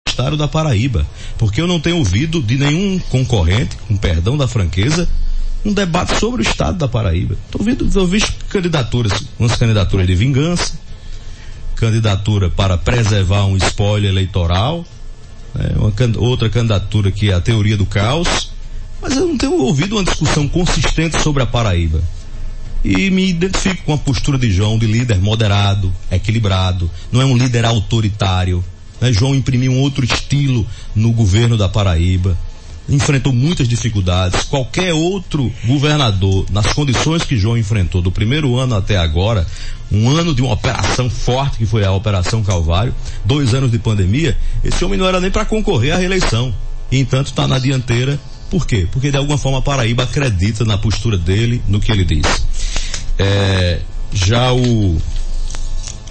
As declarações do comunicador repercutiram no programa Arapuan Verdade.